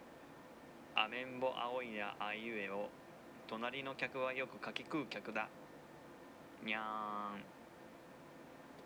(少し距離があるため弱めです)
indexスピーカー